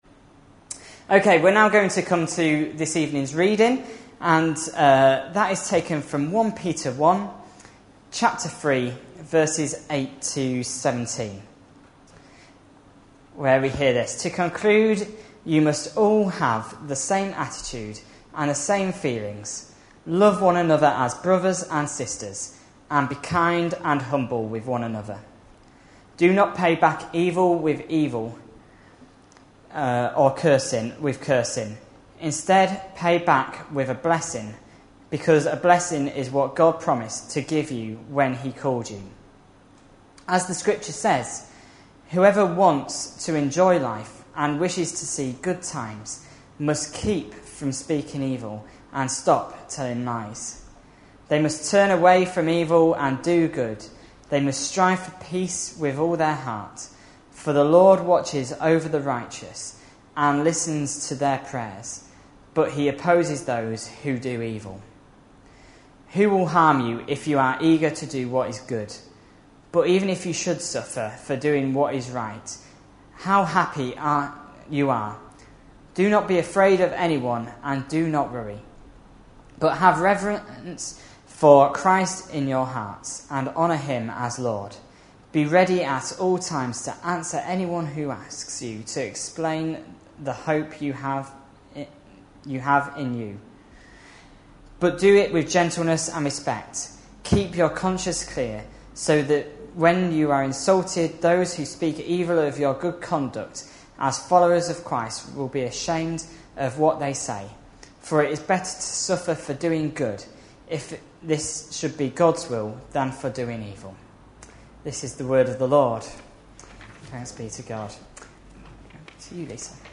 A sermon preached on 5th August, 2012, as part of our Olympic Ideals series.